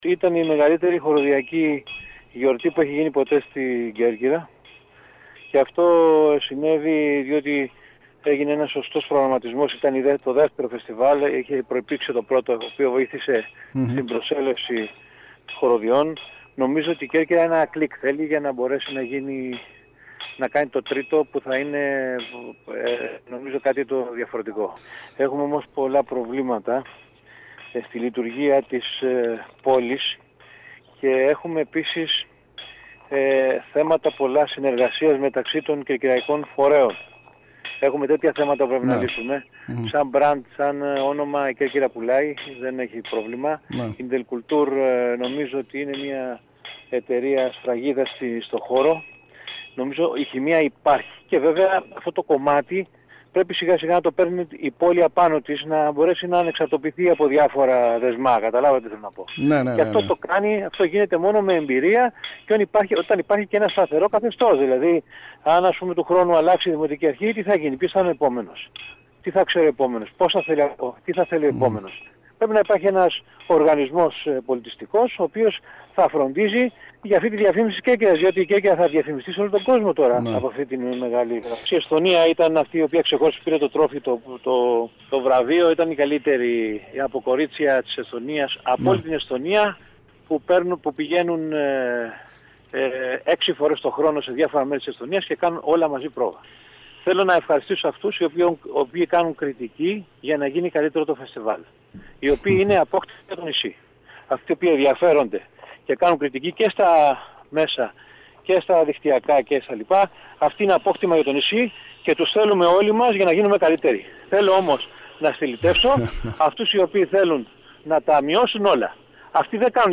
μιλώντας στο σταθμό μας